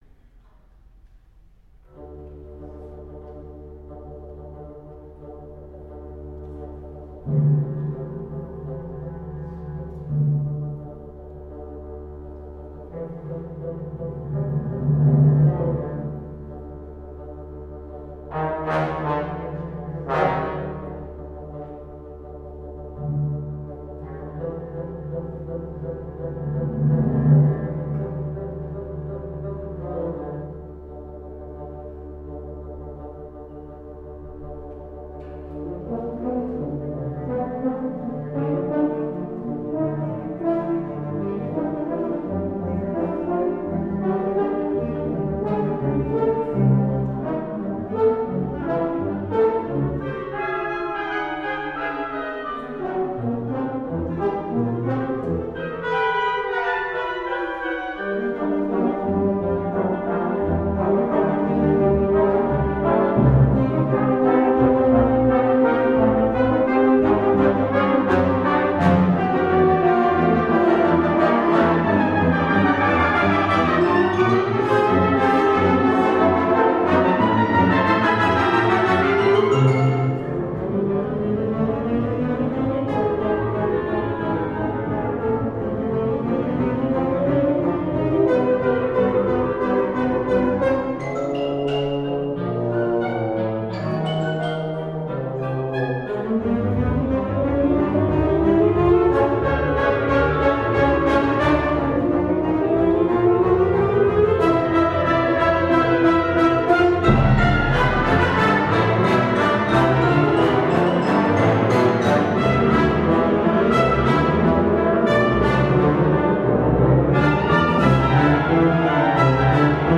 на духовой оркестр